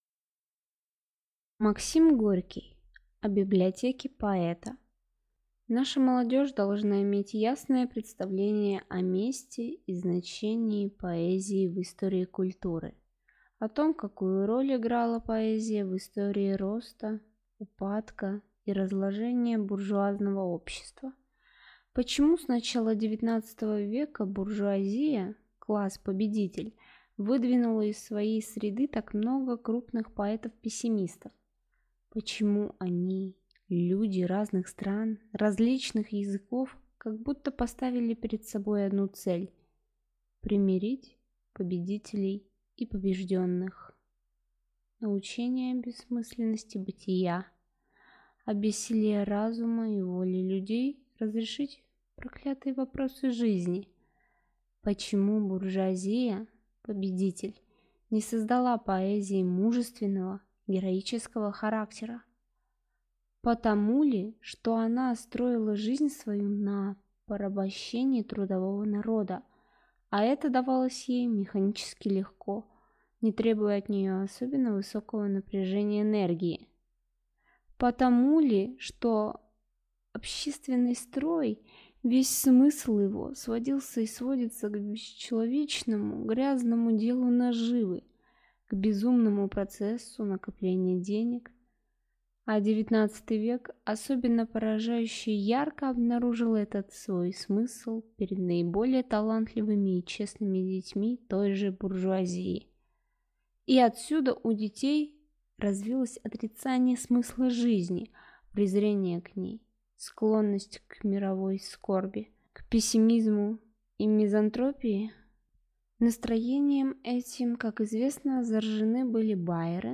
Аудиокнига О «библиотеке поэта» | Библиотека аудиокниг